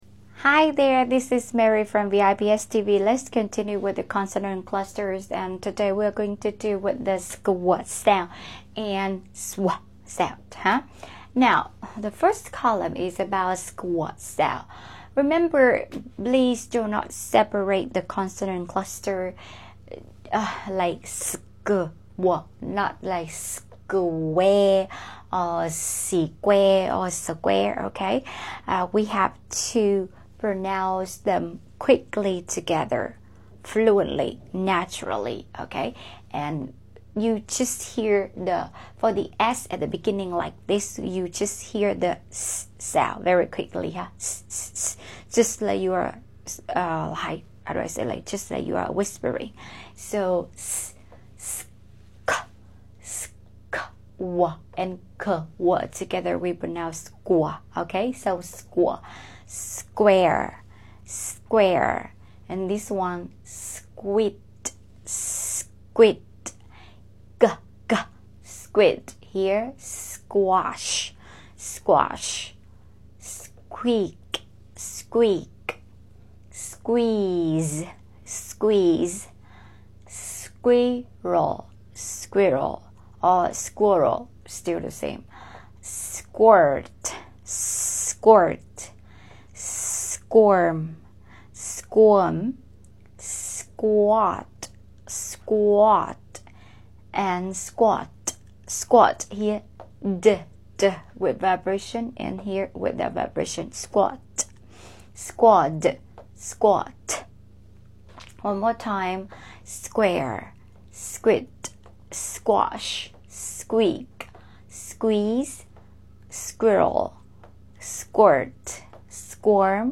How to pronounce skw and sw sounds in English | Consonant cluster | Consonant blend | IPA